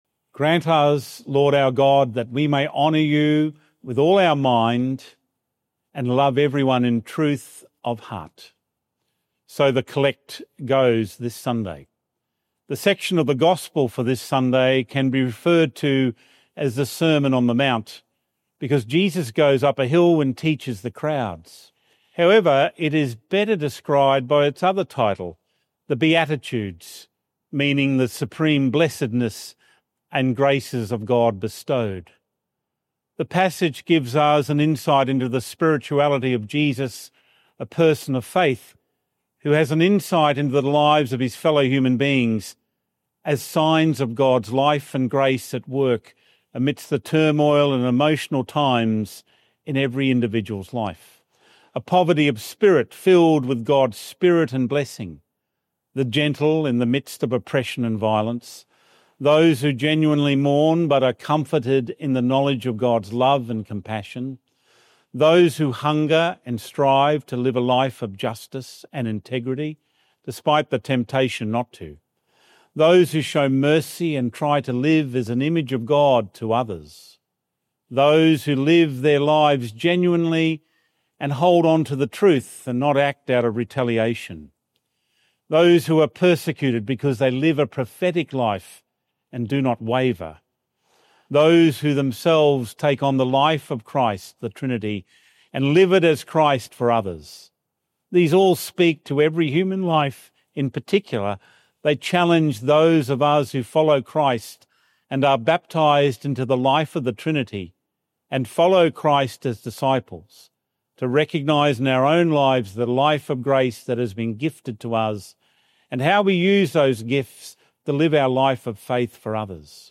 Fourth Sunday in Ordinary Time - Two-Minute Homily